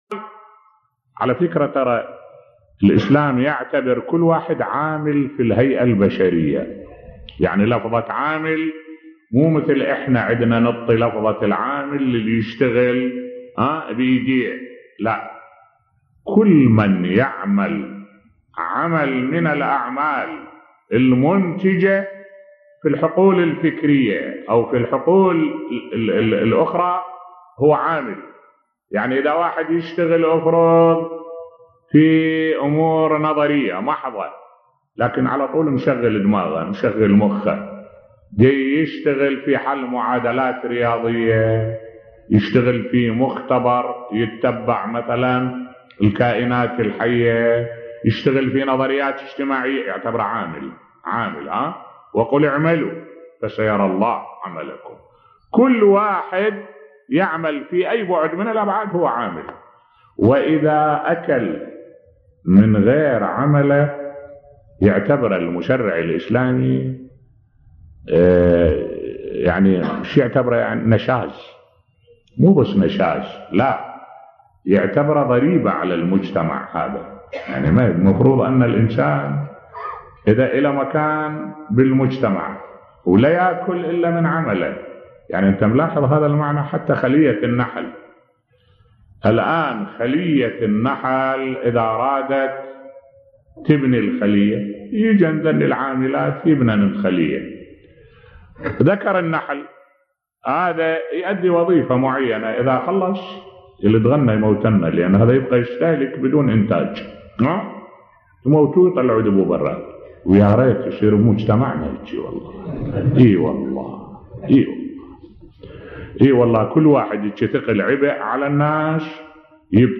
ملف صوتی حكم الأكل من كد اليد في الاسلام بصوت الشيخ الدكتور أحمد الوائلي